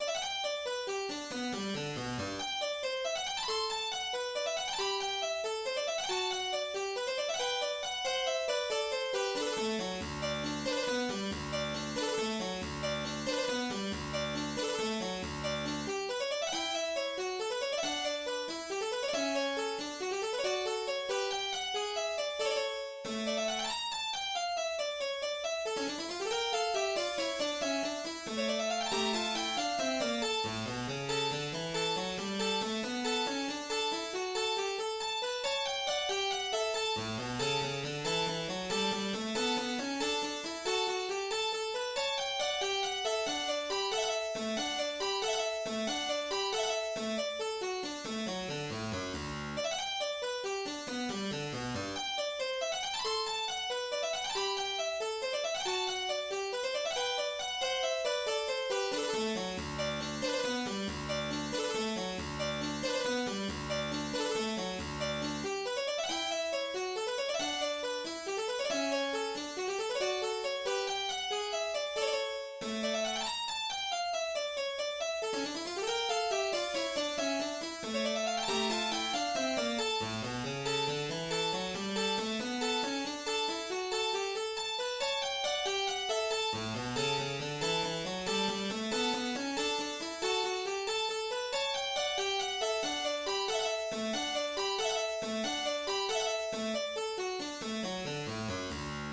sol majeur
La sonate K. 14, en sol majeur, est notée Presto.